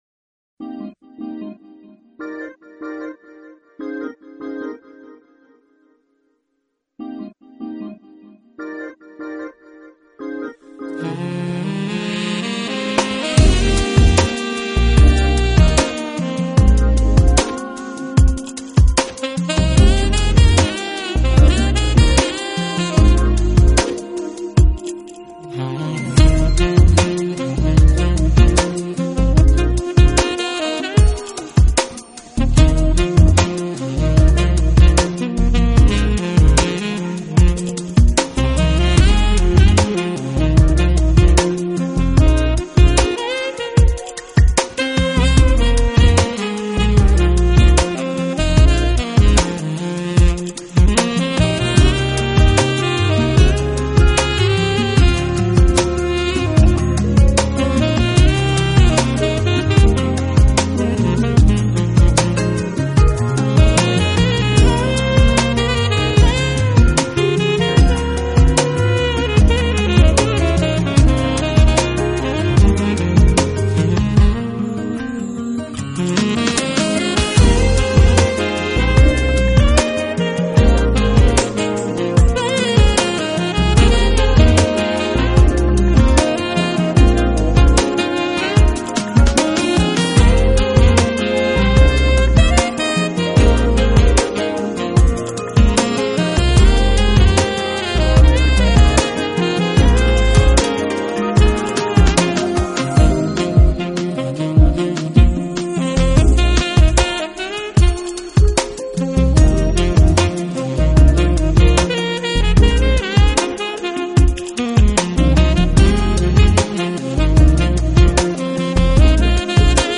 【爵士萨克斯】
曲风直率淋漓，荡气回肠。
风格偏于布